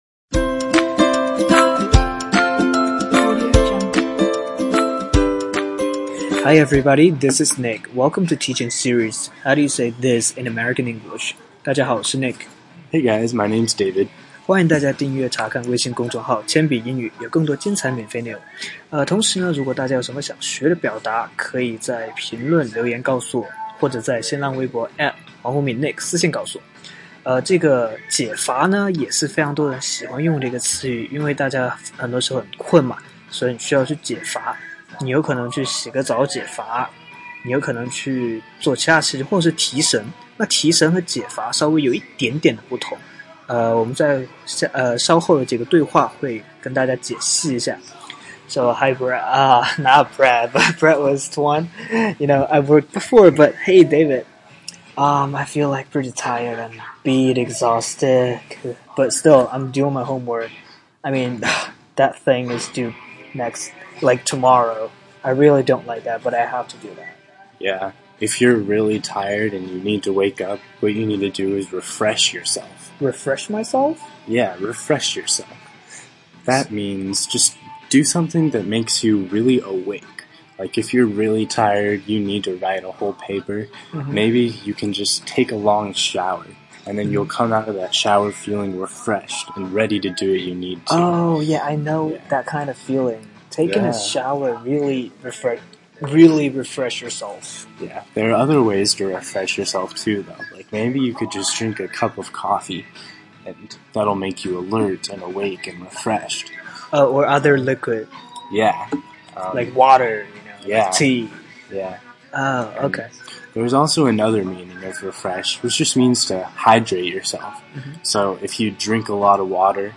在线英语听力室全网最酷美语怎么说:第36期 解乏的听力文件下载, 《全网最酷美语怎么说》栏目是一档中外教日播教学节目，致力于帮大家解决“就在嘴边却出不出口”的难题，摆脱中式英语，学习最IN最地道的表达。音频中汉语及英语交叉出现，适合不同层次的英语学习者。